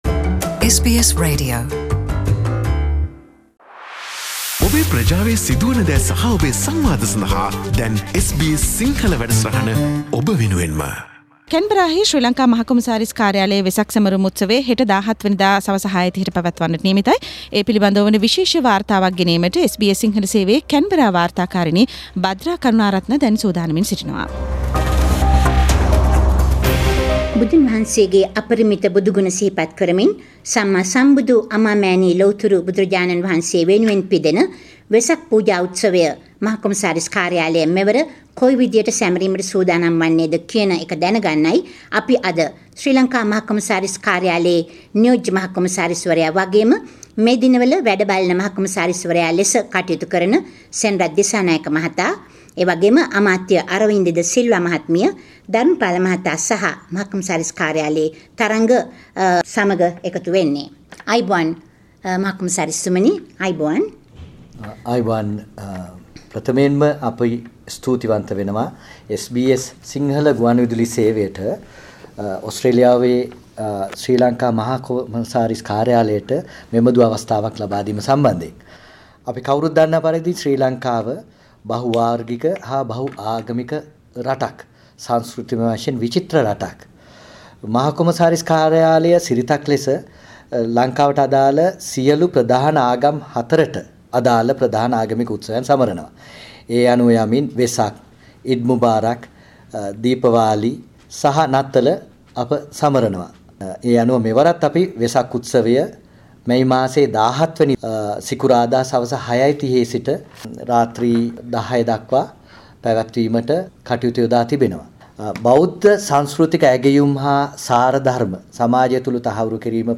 වාර්තාවක්